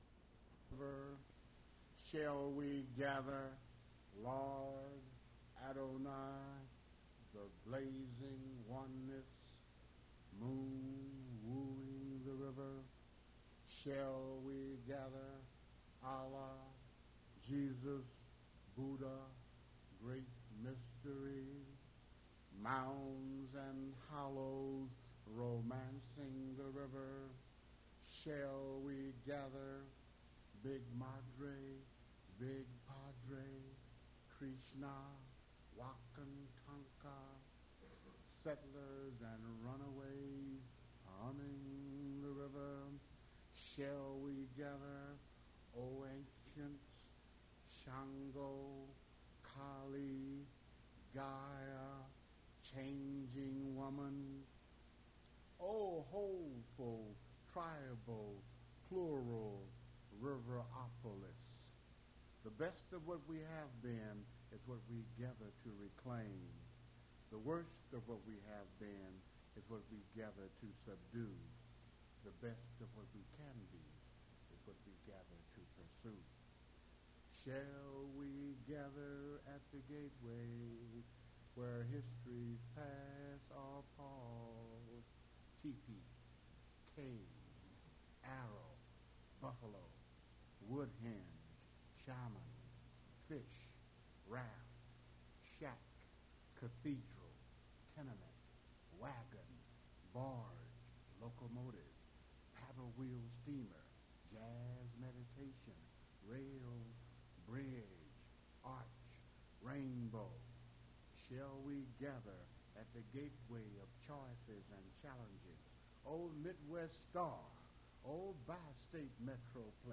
mp3 edited access file was created from unedited access file which was sourced from preservation WAV file that was generated from original audio cassette. Language English Series River Styx at Duff's Note Recording starts in the middle of the performance; volume very faint; during 47:27-47:38 recording is blank.